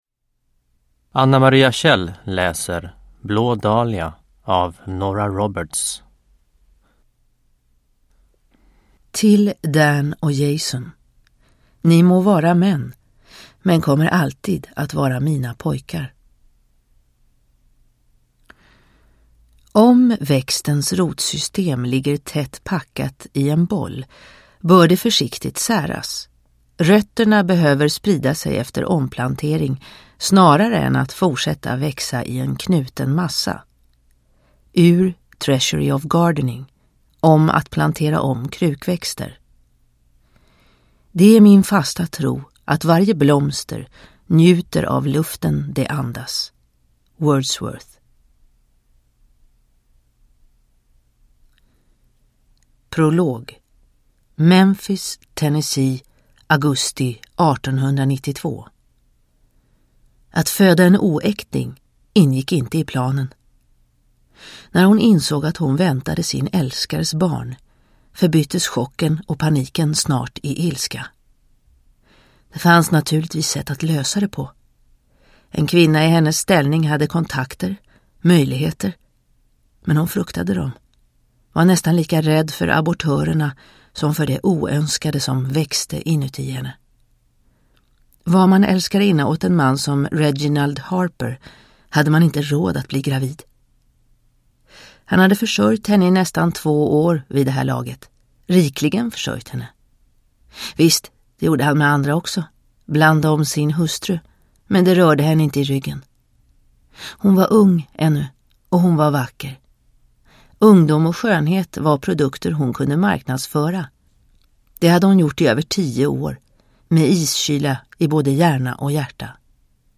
Blå dahlia – Ljudbok – Laddas ner